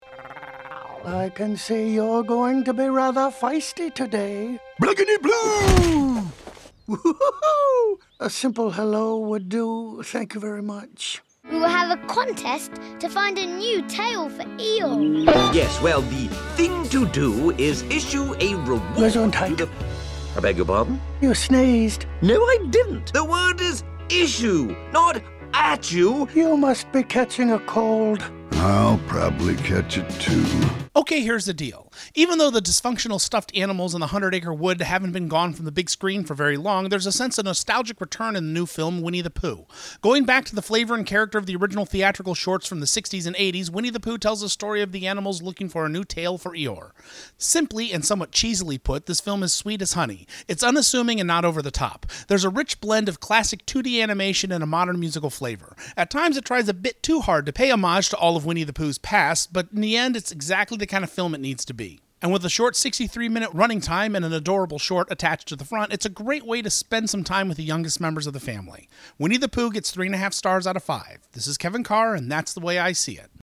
Download this Review